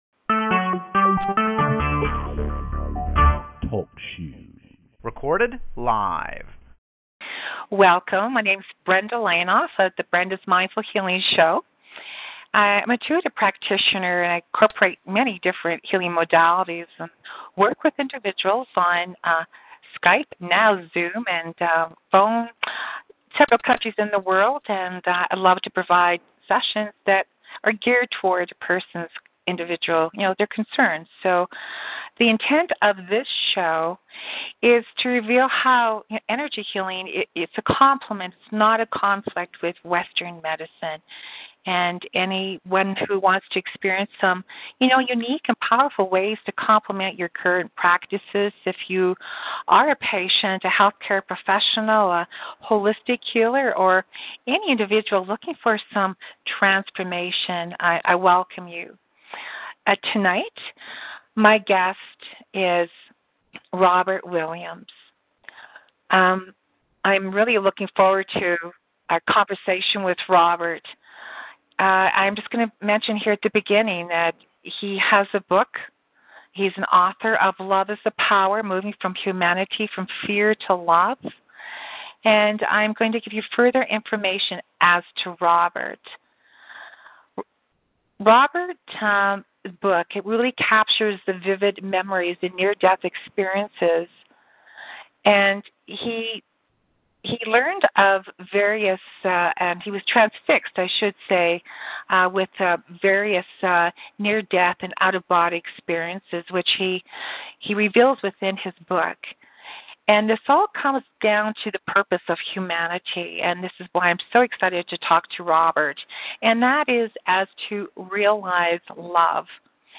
Highlights of the interview: